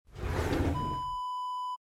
Desk drawer open sound effect .wav #1
Description: The sound of a desk drawer being opened
Properties: 48.000 kHz 16-bit Stereo
A beep sound is embedded in the audio preview file but it is not present in the high resolution downloadable wav file.
Keywords: desk, table, drawer, pull, pulling, open, opening
drawer-desk-open-preview-1.mp3